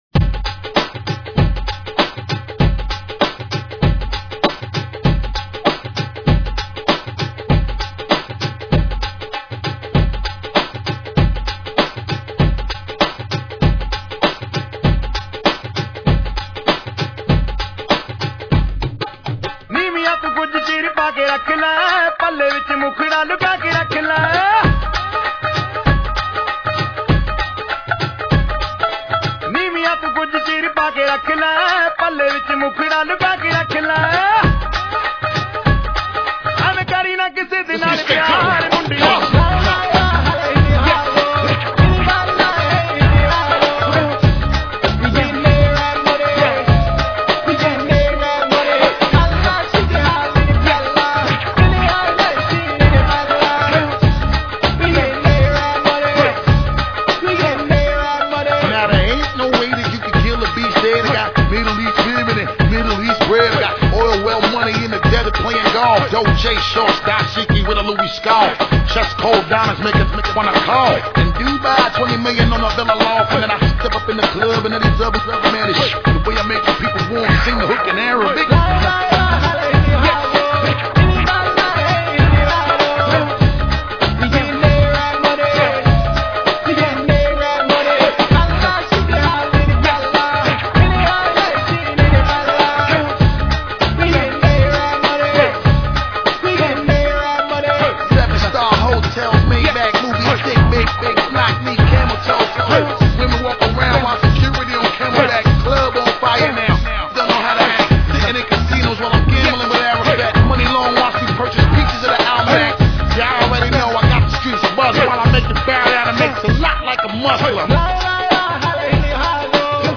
A perfect mash of two amazing tracks!
Dancefloor tested & approved.